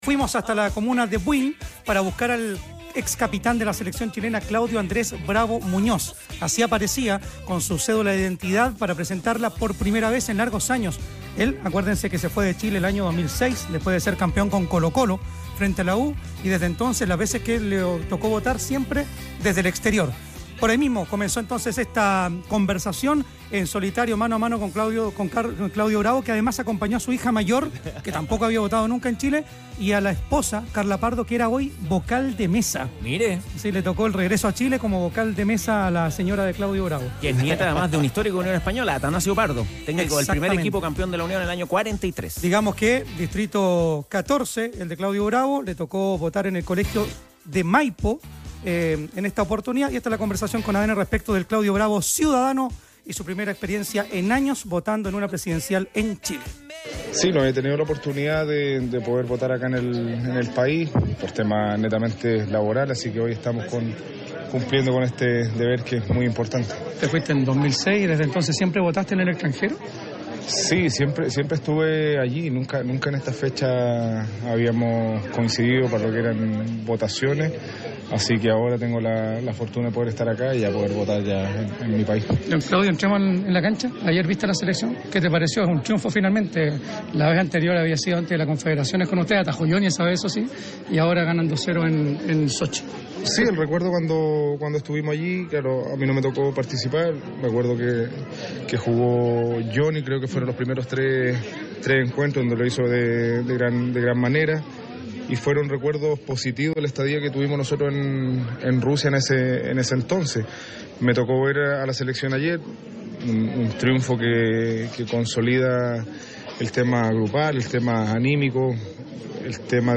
El exportero nacional conversó con Los Tenores tras cumplir con su deber cívico en Buin. También analizó la victoria de la selección chilena sobre Rusia y destacó el buen nivel de Lawrence Vigouroux.